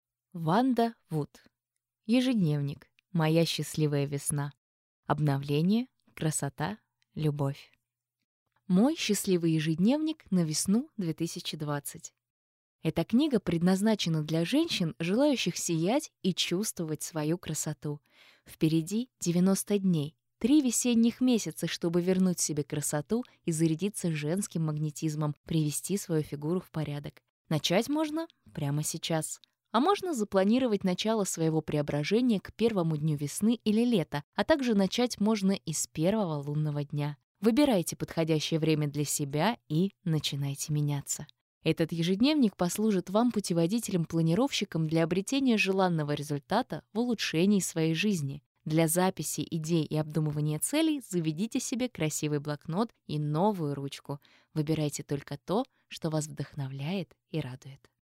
Аудиокнига Ежедневник. Моя счастливая весна. Обновление. Красота. Любовь | Библиотека аудиокниг